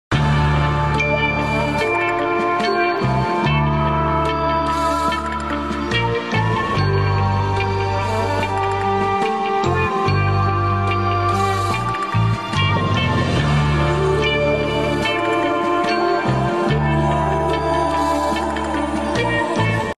Bit of a spaghetti western